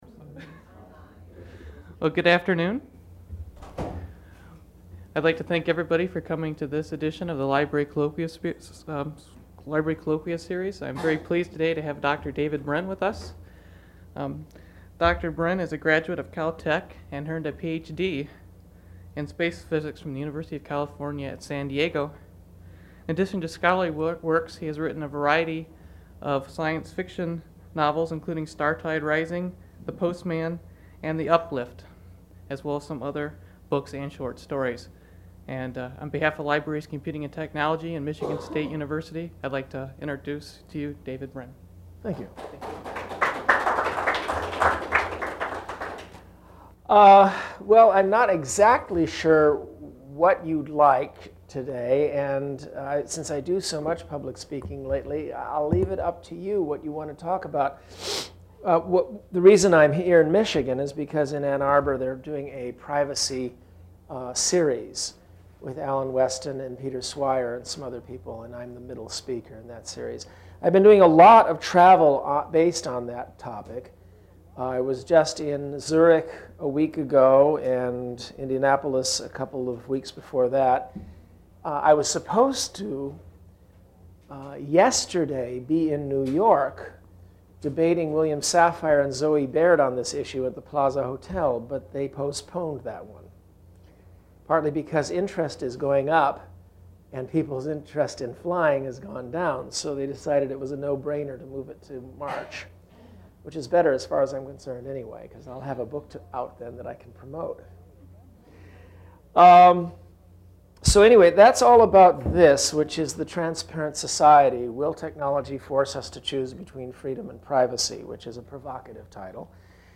Brin describes the new "security society" of surveillance, search and restriction. He says the tradeoff is neither necessary nor real. Brin answers questions from the audience.
Held in the Main Library.